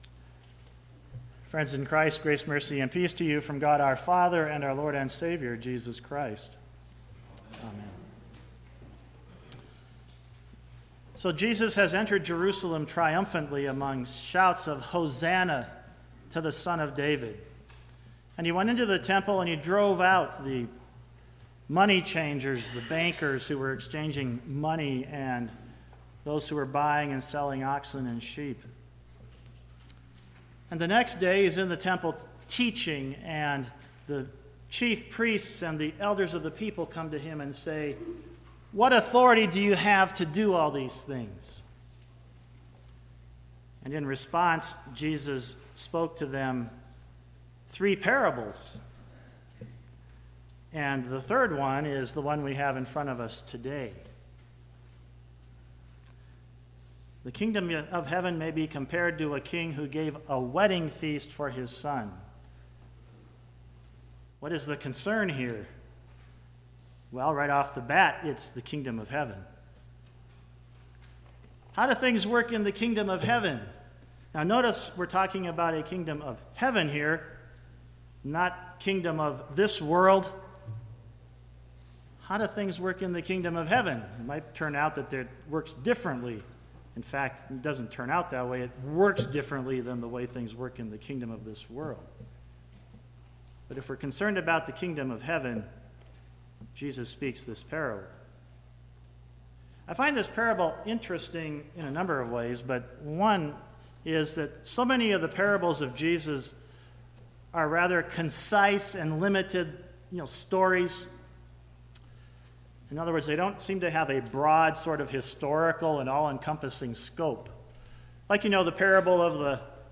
Sermon - 10/14/2018 - Wheat Ridge Lutheran Church, Wheat Ridge, Colorado